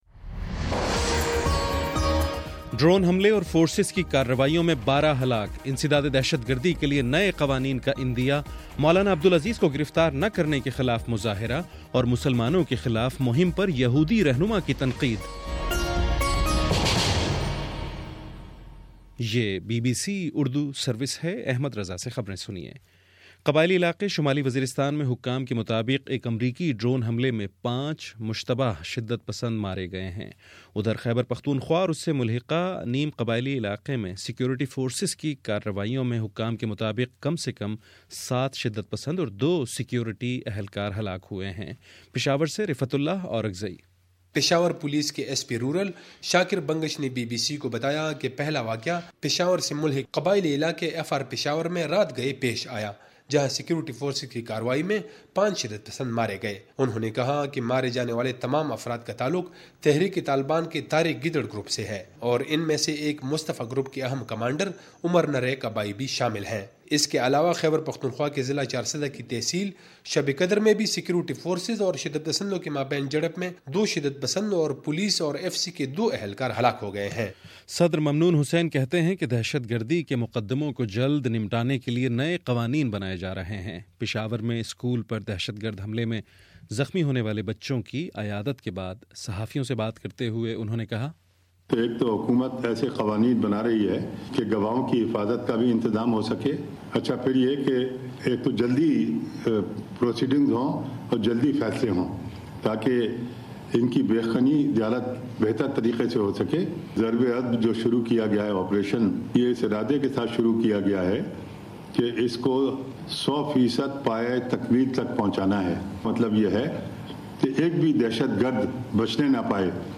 دسمبر 20 : شام سات بجے کا نیوز بُلیٹن